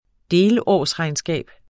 Udtale [ ˈdeːlˌɒˀs- ]